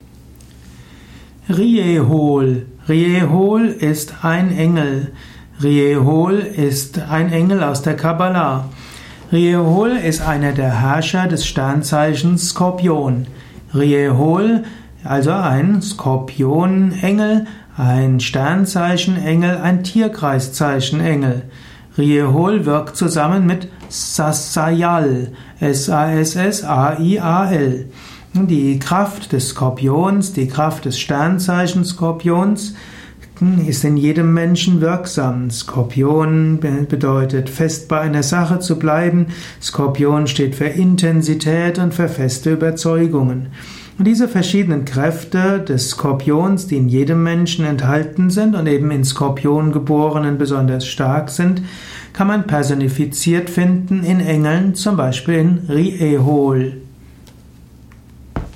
Dieser Podcast ist die Tonspur eines Engelsvideos.